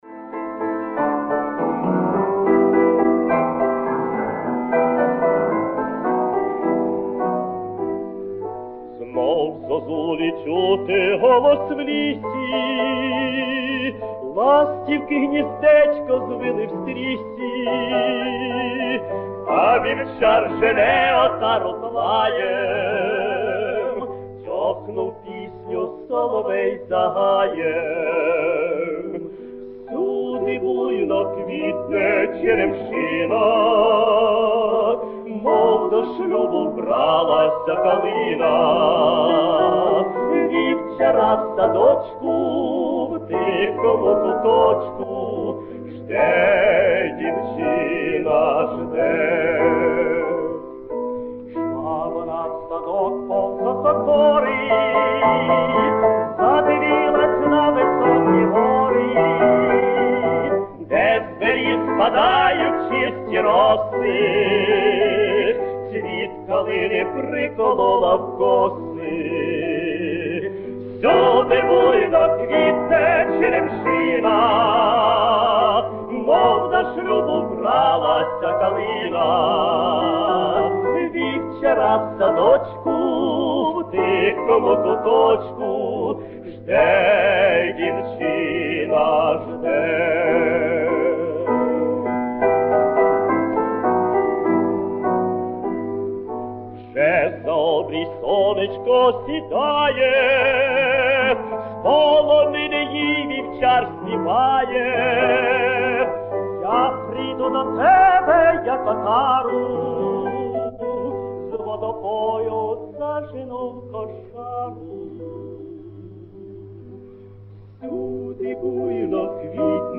Поют между прочим некогда известные заслуженные артисты УССР